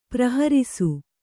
♪ praharisu